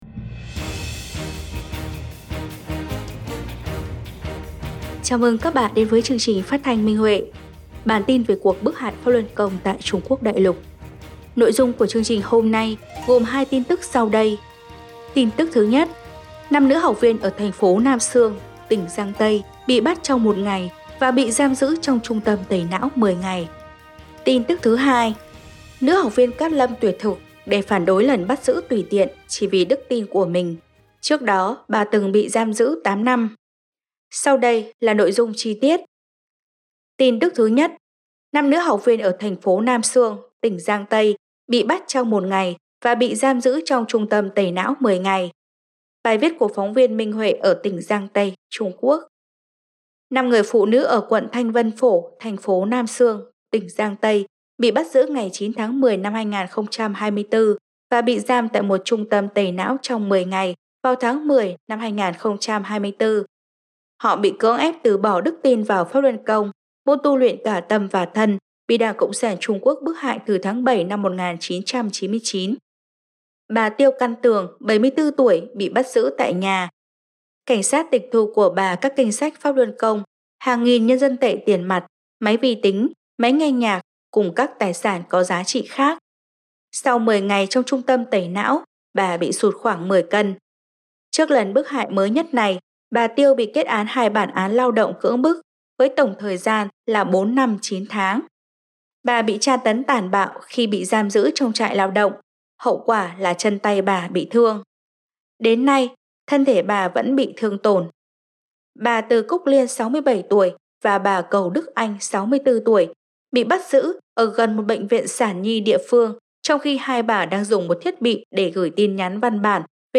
Chương trình phát thanh số 154: Tin tức Pháp Luân Đại Pháp tại Đại Lục – Ngày 12/11/2024